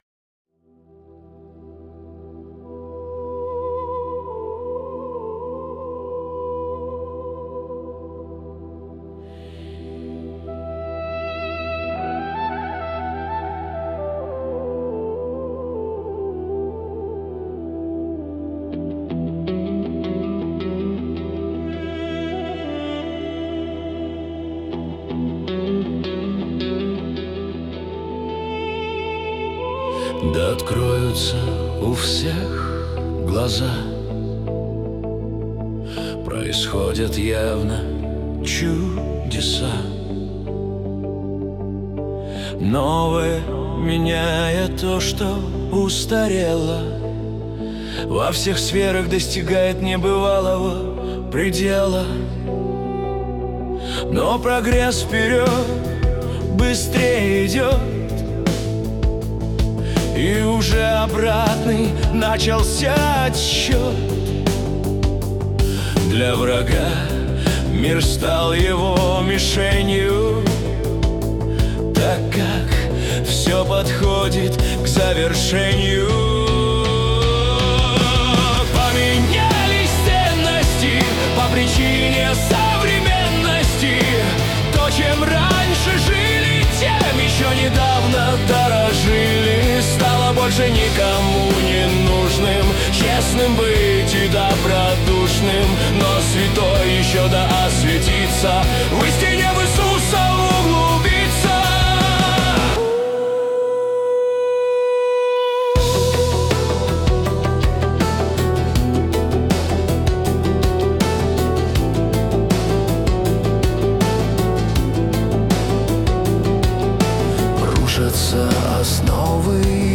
песня ai
95 просмотров 416 прослушиваний 31 скачиваний BPM: 80